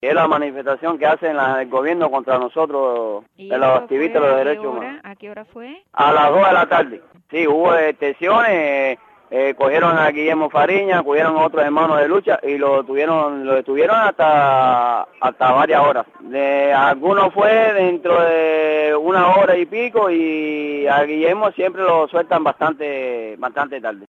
Santa Clara reportero ciudadano 1